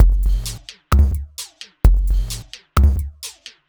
Grimetime Break 130.wav